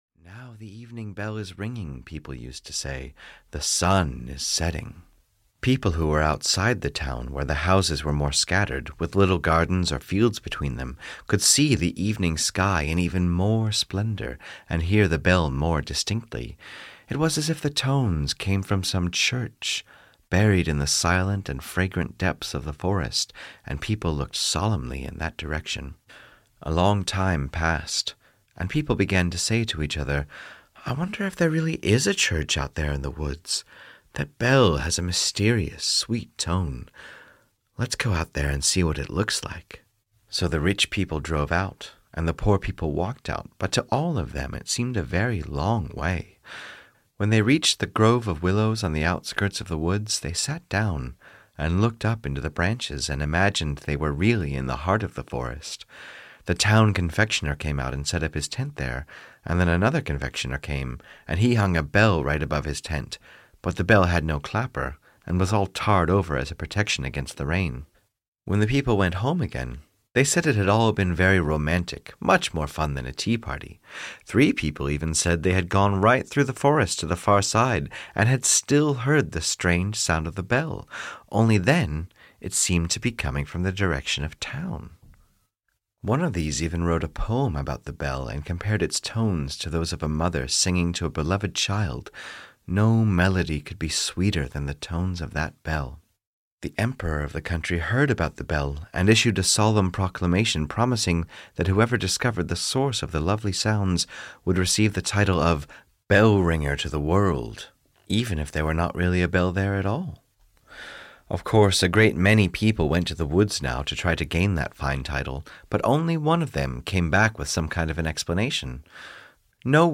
The Bell (EN) audiokniha
Ukázka z knihy